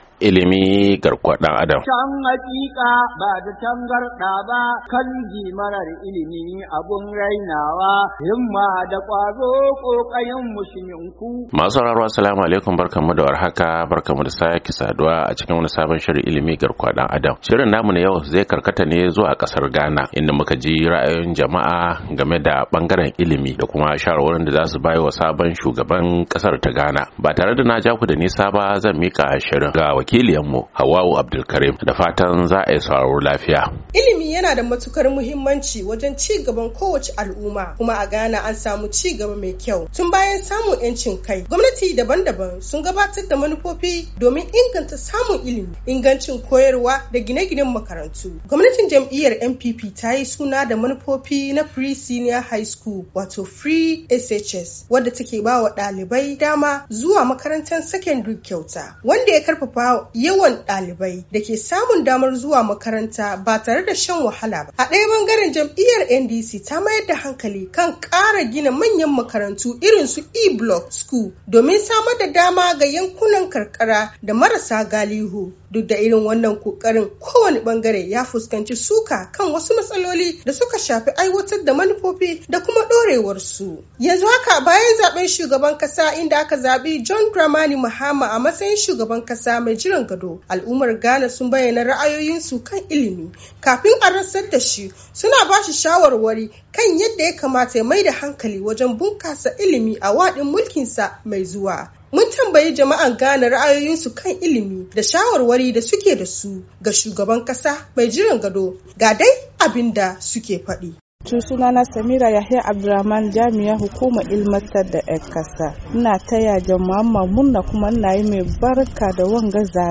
A shirin Ilimi na wannan makon mun tattauna ne da wasu 'yan Ghana bayan zaben shugaban kasar inda suka bayyana shawarwarin su game de bangaren ilimi a kasar.